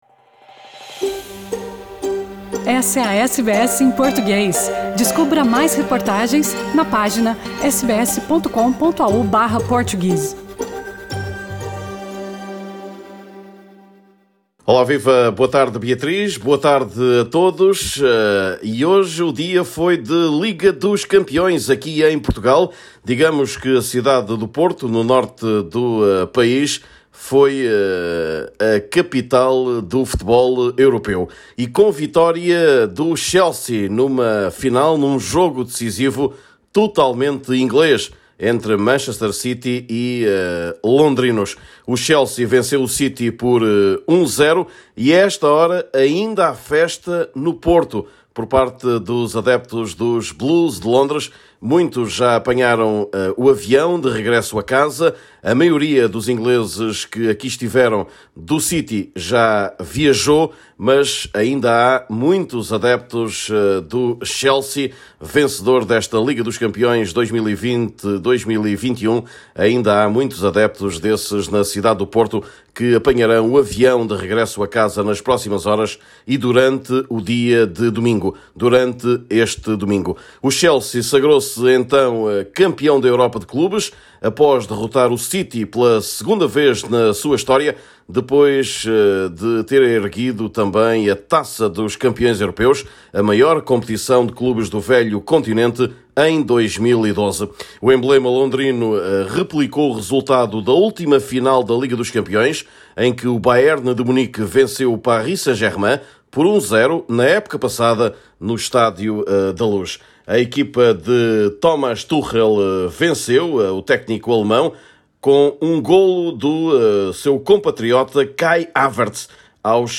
boletim desportivo